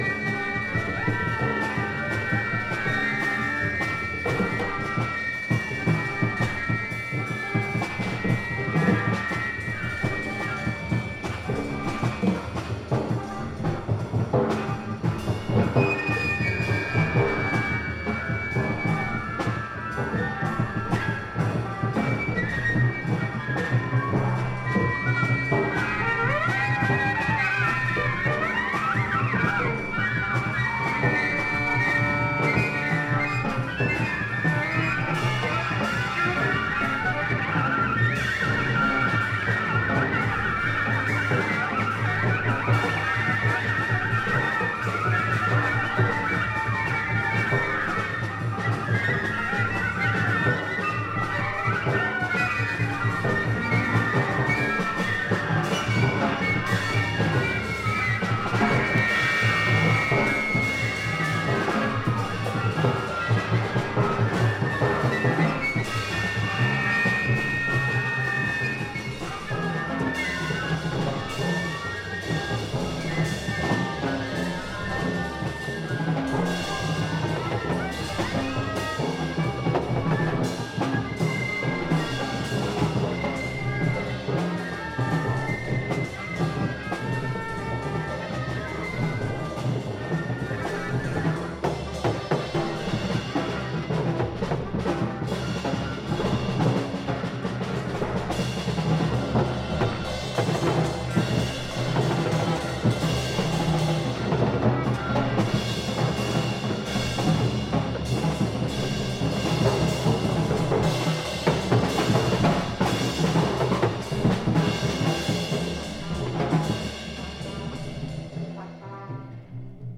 Baladas e noise convivem porque sim.